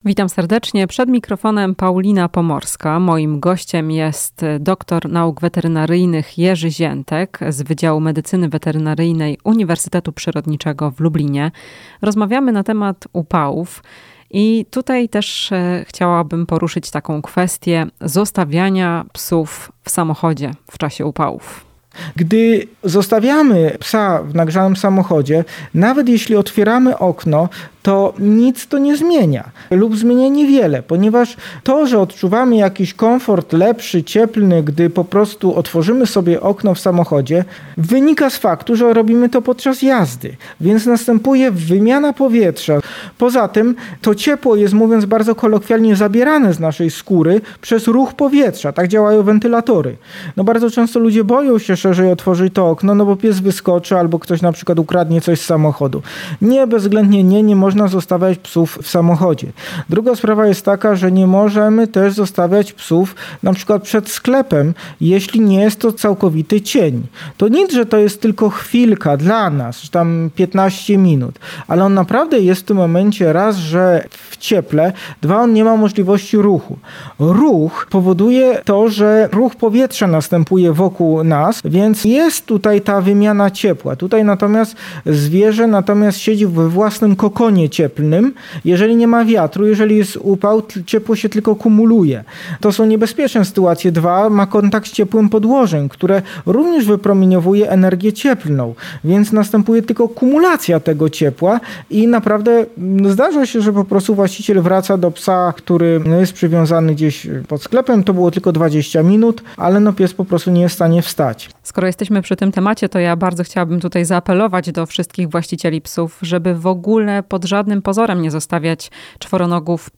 W „Chwili dla pupila” poruszamy temat zostawiania psów w samochodzie lub przed sklepem w czasie upałów. Rozmowa z dr. n. wet.